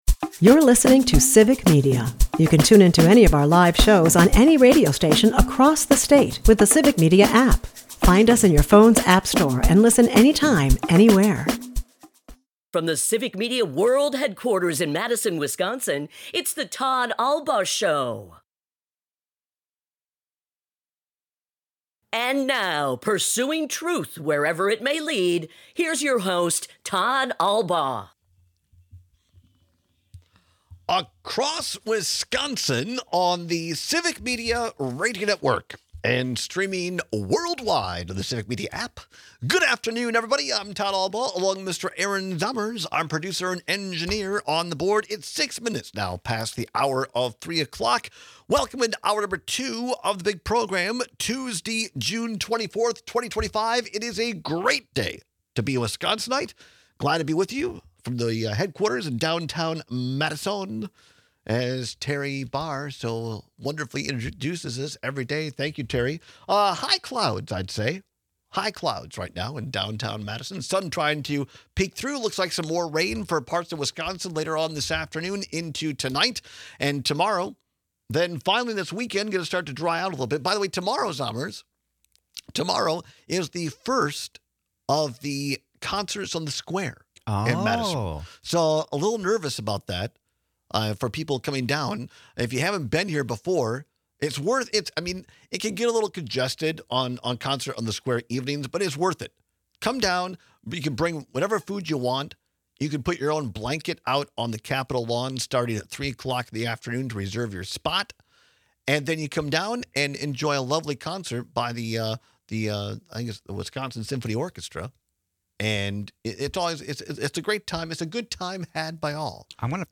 He joins us in-studio to provide an update on the status of the Knowles-Nelson Stewardship Fund amidst chaotic state budget negotiations.